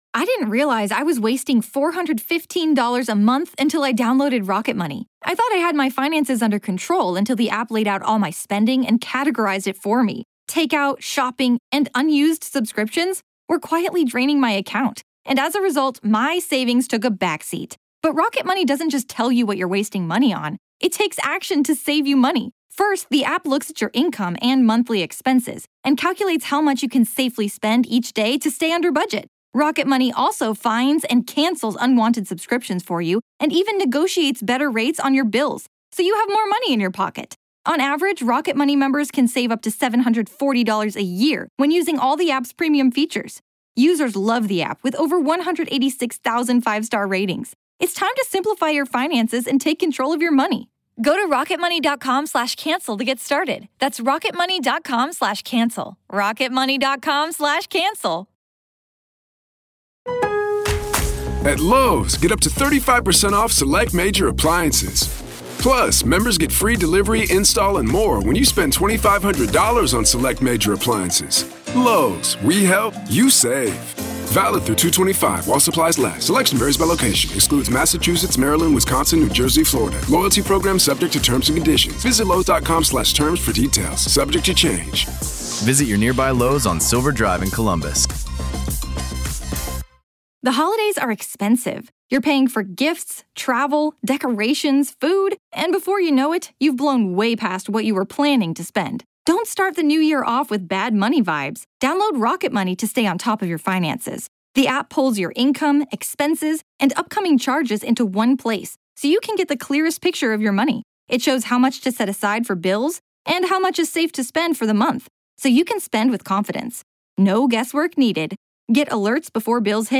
This is a daily EXTRA from The Grave Talks. Grave Confessions is an extra daily dose of true paranormal ghost stories told by the people who survived them!